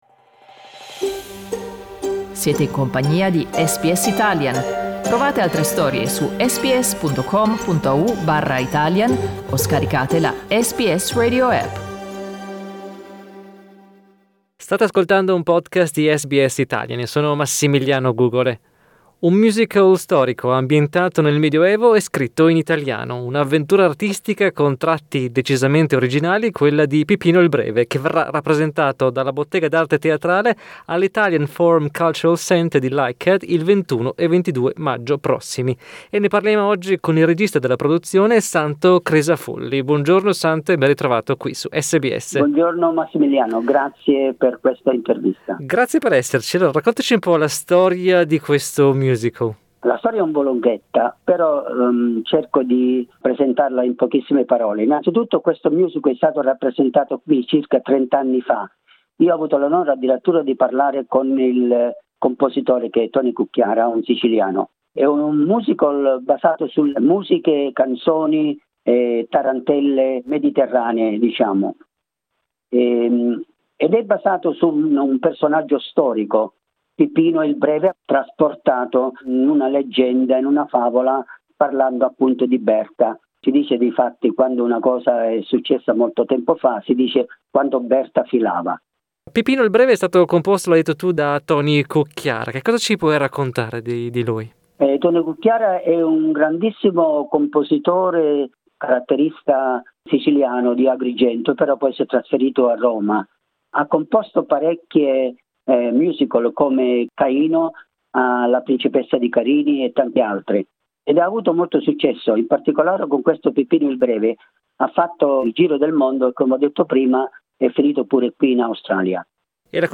Fare teatro in italiano è uno dei mezzi più efficaci per trasmettere la lingua e la cultura italiana. Ascolta l'intervista